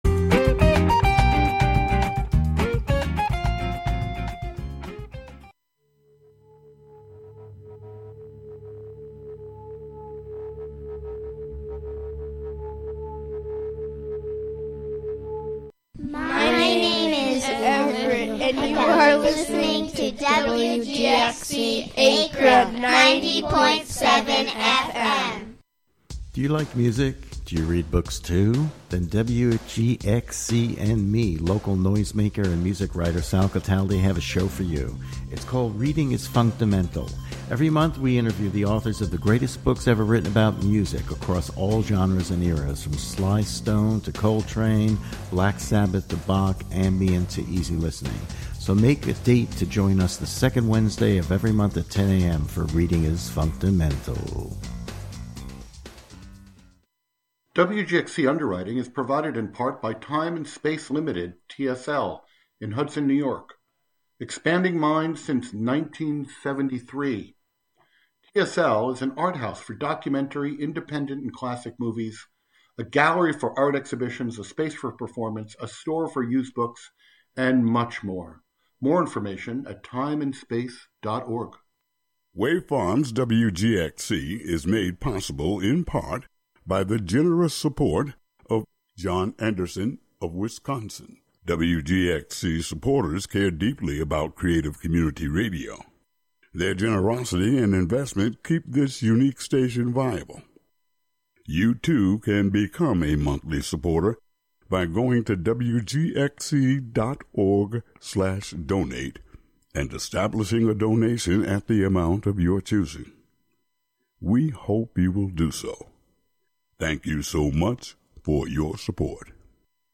To extend this idea into practice, other non-human forms and systems will co-host the show, speak, and sing.